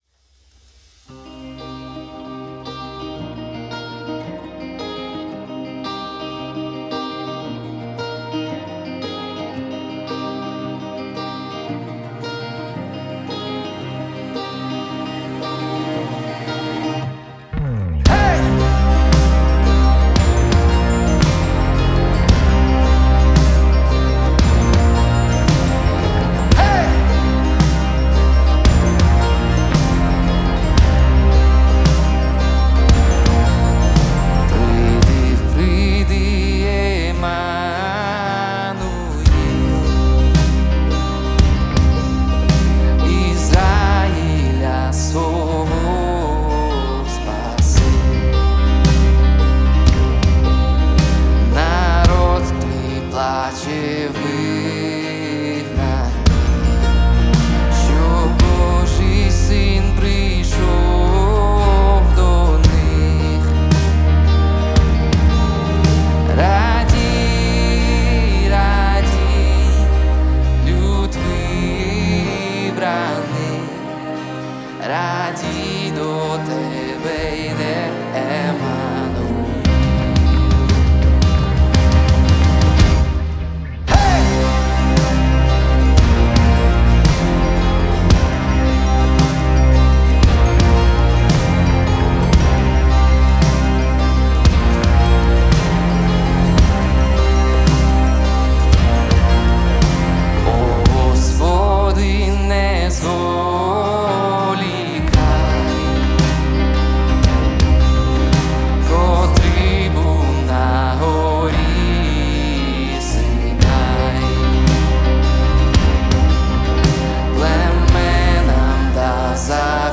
379 просмотров 14 прослушиваний 2 скачивания BPM: 169